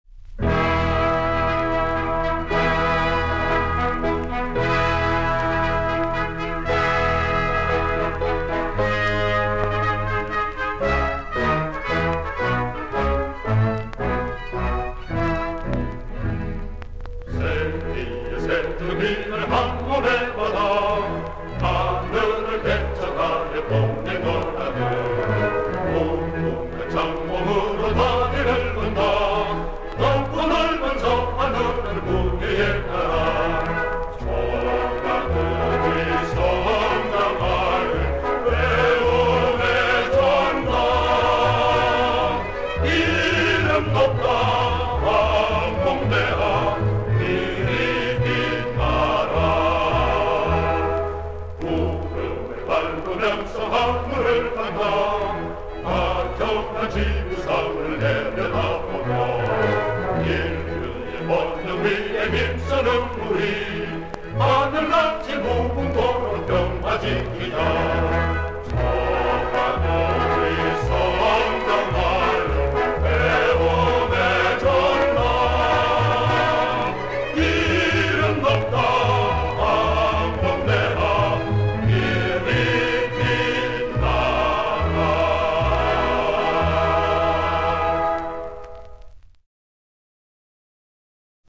교가(校歌)
kau_song.mp3